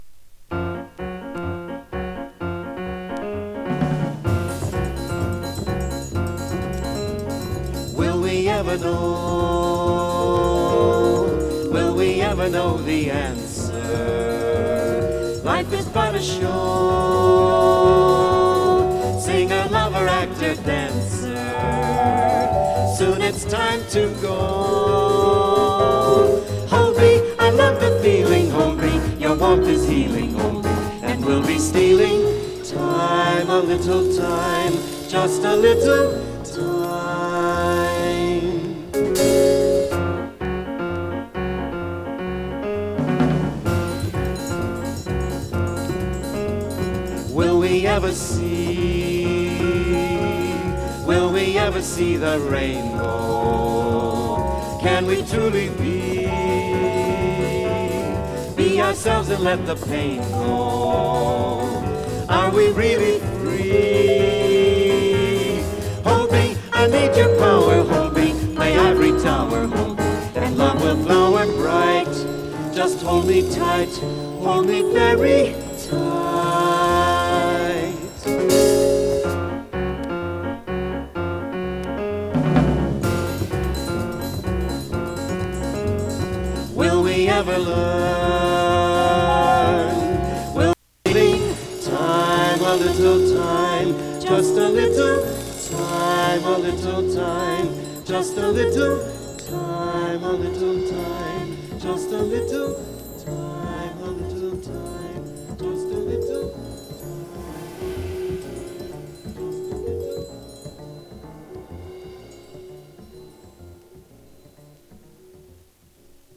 ＊時おり軽いチリ/パチ・ノイズ。